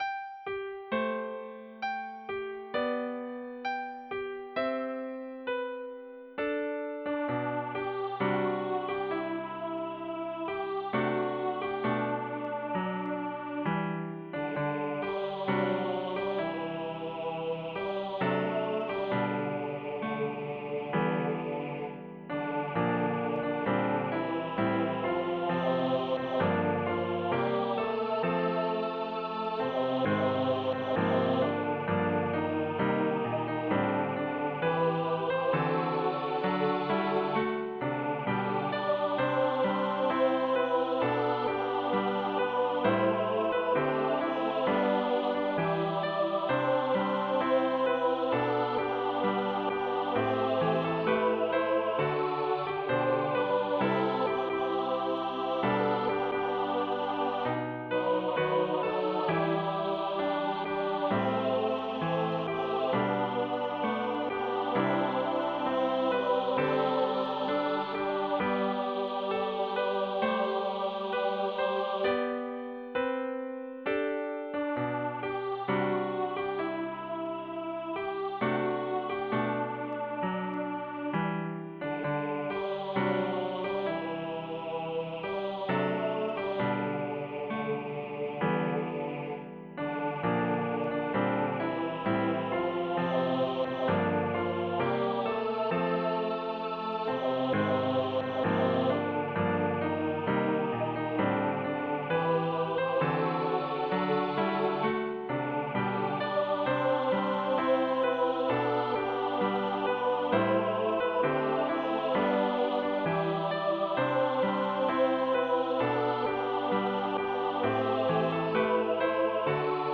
On m'a demandé de créer un arrangement choral de l'hymne n° 1039, intitulé « Because », avec un chant à l'unisson dans les couplets et une harmonie à trois voix (soprano, alto, basse) dans le refrain.
Sont incluses une partition des parties vocales d'une page et un fichier audio généré par ordinateur.
Voicing/Instrumentation: SAB